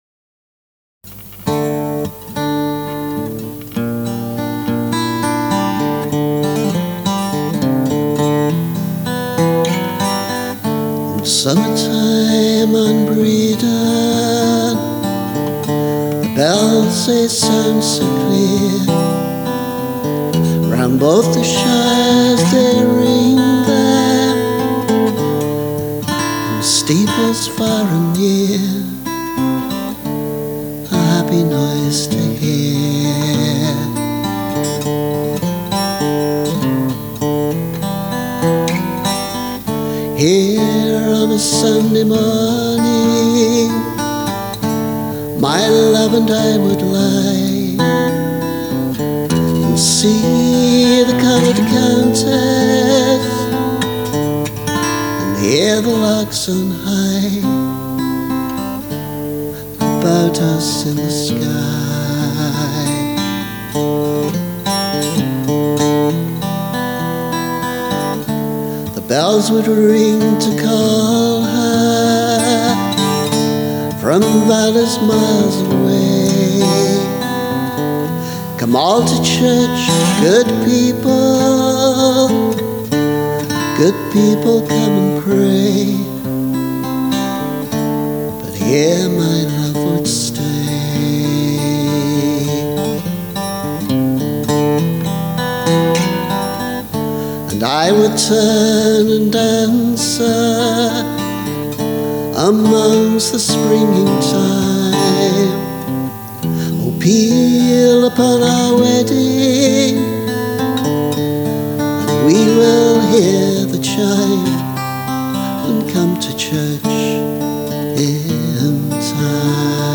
* Pronounced Breedon.